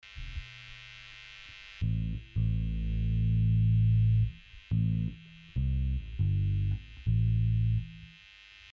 Шум в студии
С недавних пор возник какой то шум в студии, и он очень сильный.
При подключении любой гитары, любым проводом в гитарный усилитель, либо напрямую в аудиоинтерфейс - он есть. Если менять положение гитары в комнате - он то усиливается то пропадает, причем что даже небольшой наклон инструмент градусов скажем на 15 уже может убрать этот фон..